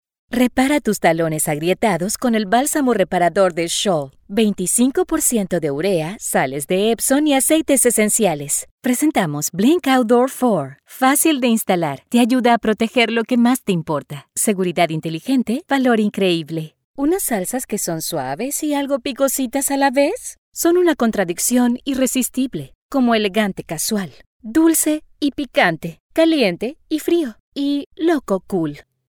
Español (Latinoamericano)
Los oyentes describen mi voz como poseedora de una rara combinación de calidez tranquilizadora y autoridad segura.
Cálido
Conversacional
Sincero